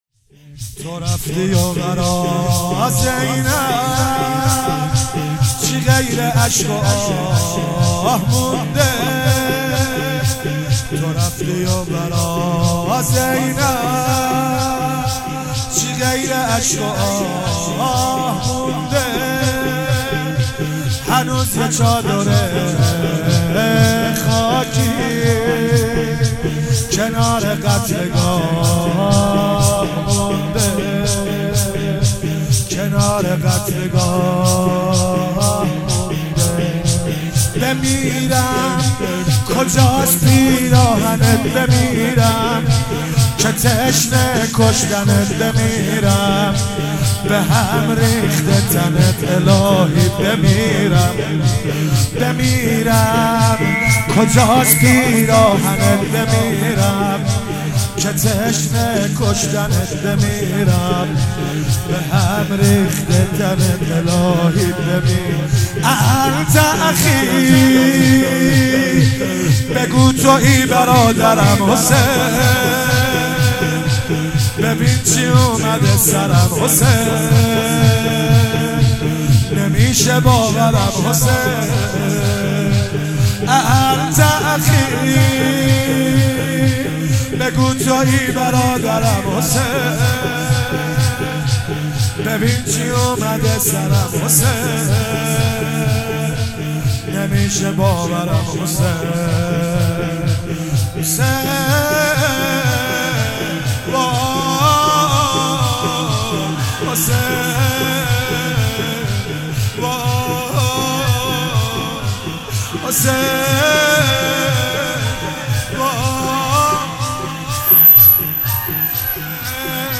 شور مداحی